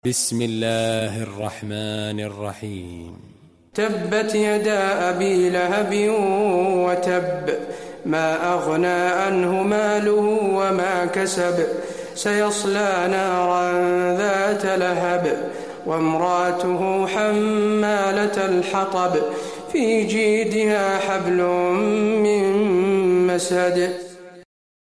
المكان: المسجد النبوي المسد